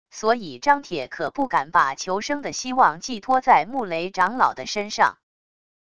所以张铁可不敢把求生的希望寄托在穆雷长老的身上wav音频生成系统WAV Audio Player